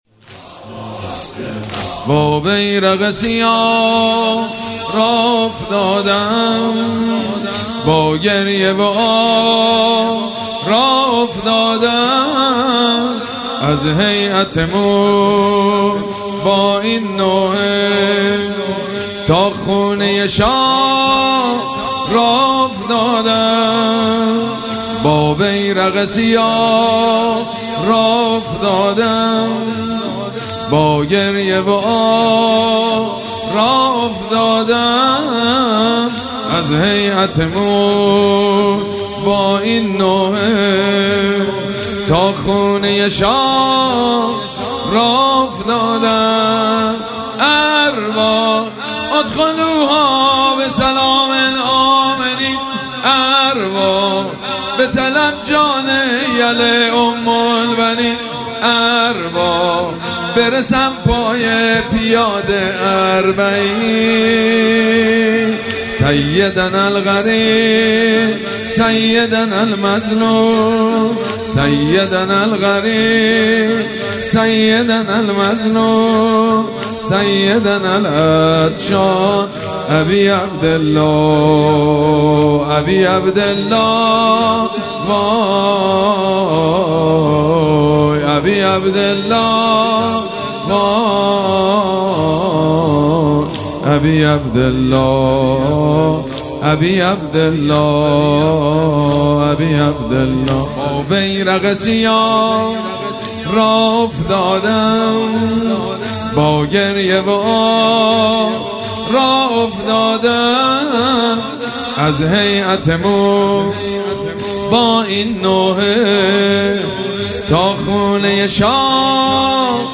شب اول مراسم عزاداری اربعین حسینی ۱۴۴۷
مداح
حاج سید مجید بنی فاطمه